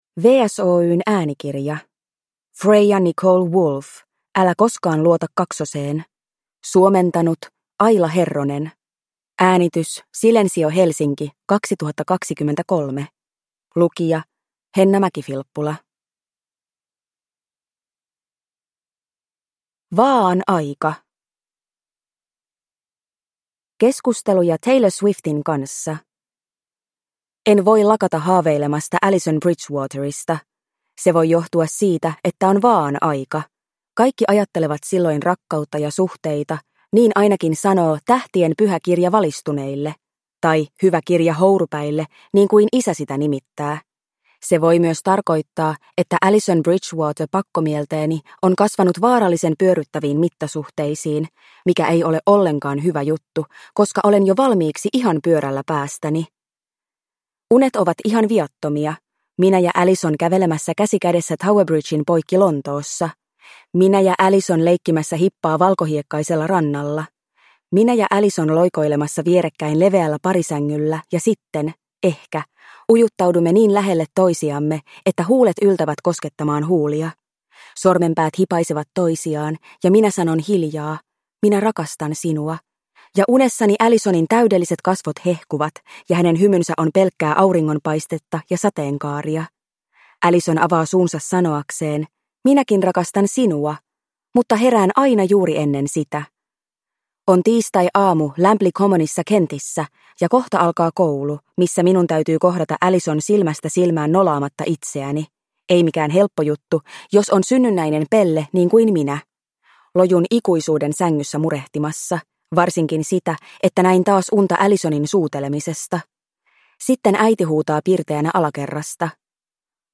Älä koskaan luota kaksoseen – Ljudbok – Laddas ner